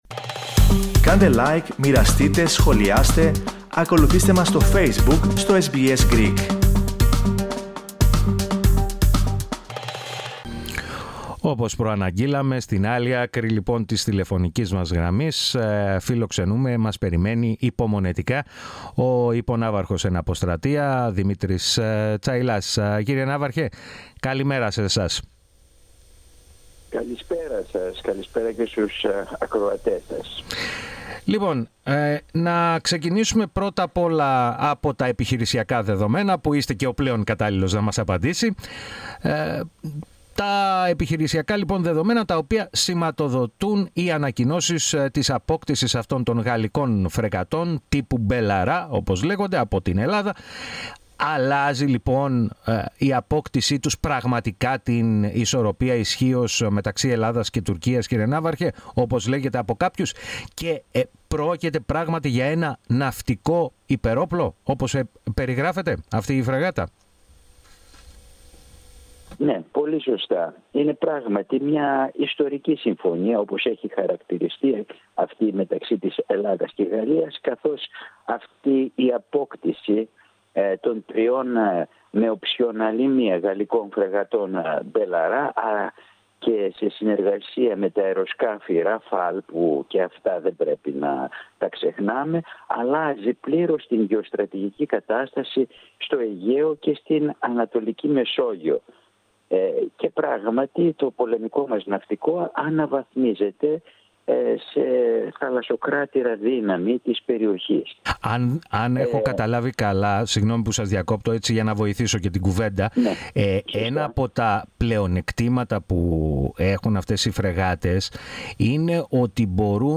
Επιπλέον, ανέλυσε τα γεωστρατηγικά δεδομένα της ελληνογαλλικής συμφωνίας, και απάντησε στο ερώτημα, κατά πόσο η αγορά αυτών των φρεγατών, οδηγεί σε μια κούρσα εξοπλισμών, μεταξύ Ελλάδας – Τουρκίας. Ακούστε ολόκληρη τη συνέντευξη, πατώντας το σύμβολο στο μέσο της κεντρικής φωτογραφίας.